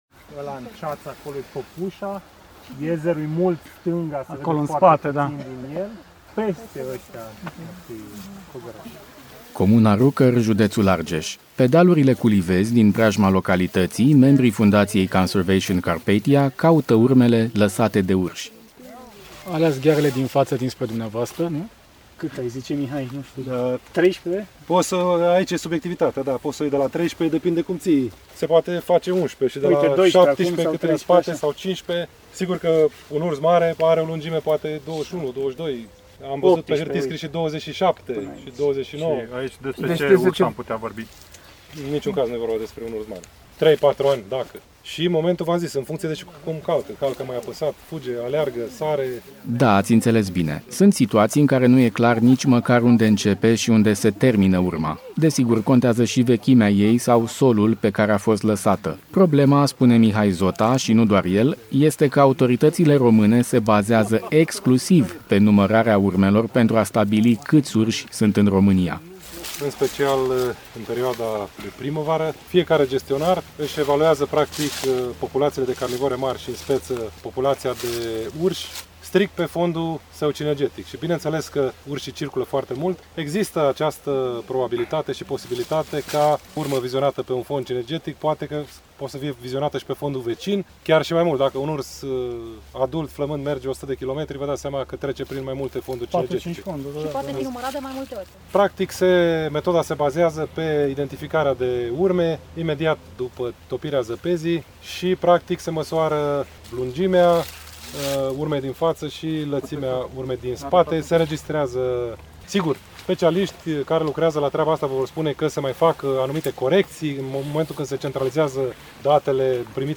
Rezultatele surprinzătoare ale primului recensământ genetic la urșii din România | REPORTAJ